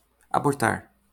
eur. port. IPA/ɐ.boɾˈtaɾ/[1]
braz. port. IPA/a.bohˈtaɾ/[1]